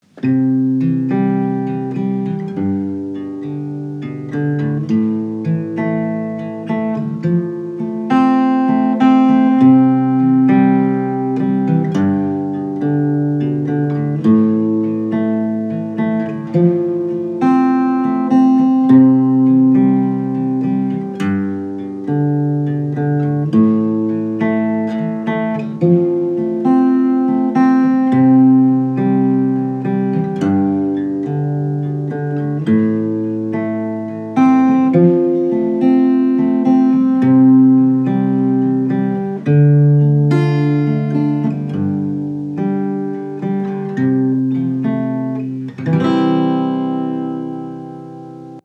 音乐疗法——第一期第二首 安慰，甚至是治愈，用简单而温暖的吉他音乐，让人们感受到亲切和温馨，找到共鸣和安慰 Comfort, even healing, using simple and warm guitar music to make people feel kind and warm, finding resonance and comfort 快来听听这首音乐，给你带来什么感受！